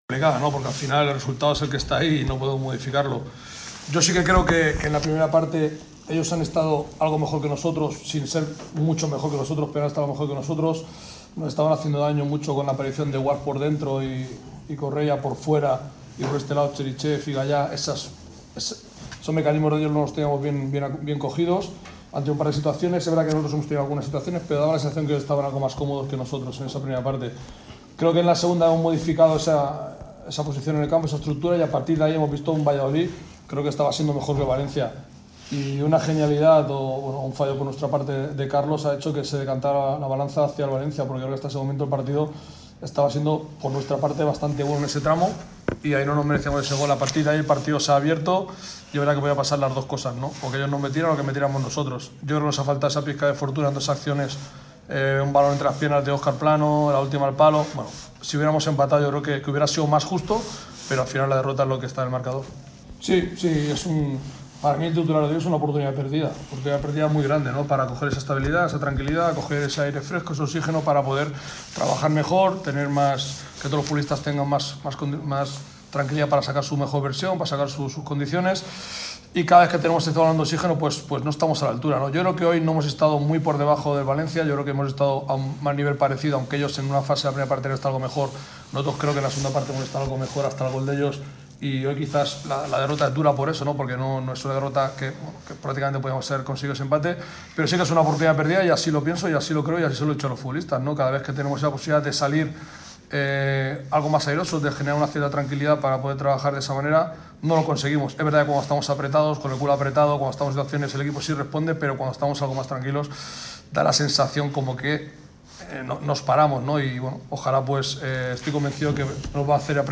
“Es una oportunidad perdida muy grande para coger ese oxígeno. Cada vez que tenemos este balón no estamos a la altura. Así se lo he dicho a los futbolistas. Cuando estamos algo más tranquilos da la sensación de que nos paramos”, expresó Sergio González en rueda de prensa.